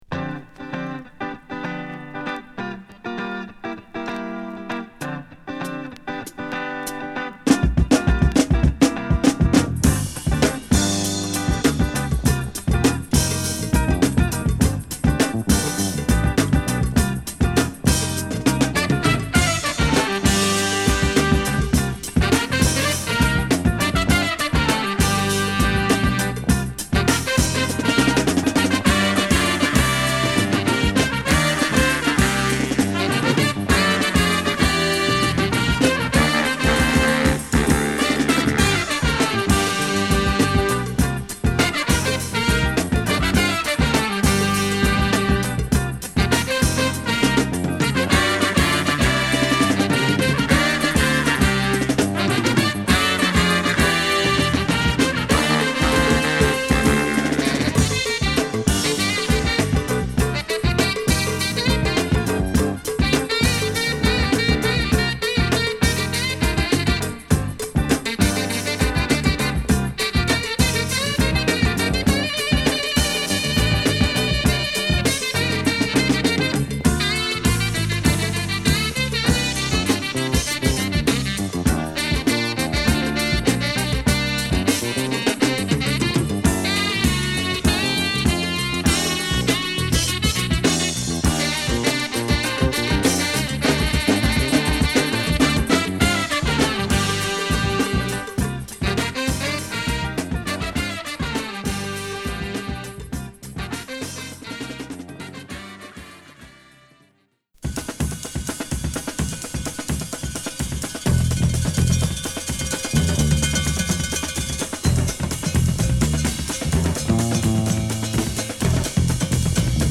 アフロ～ラテンも消化したカッコ良いファンキートラックを満載したレア・グルーヴ名盤！